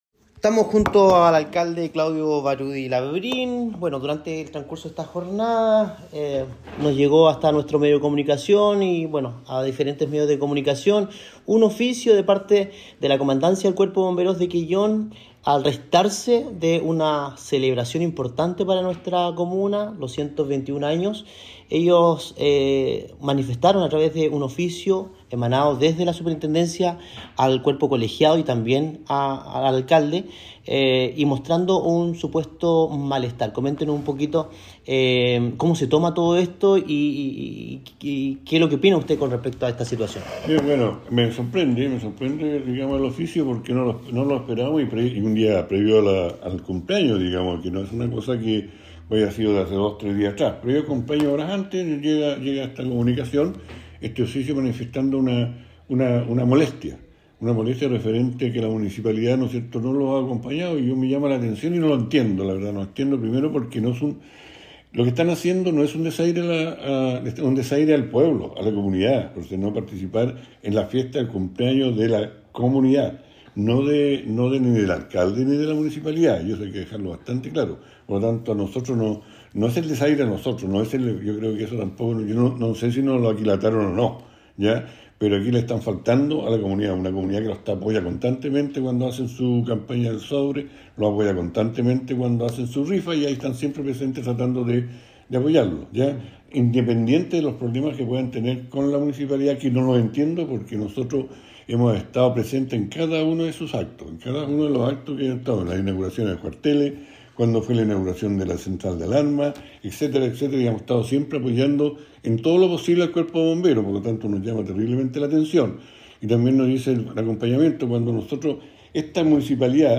05-RESPUESTA-ALCALDE-DE-QUELLON-A-OFICIO-DE-BOMBEROS.mp3